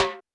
Drums_K4(30).wav